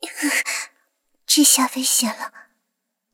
追猎者中破语音.OGG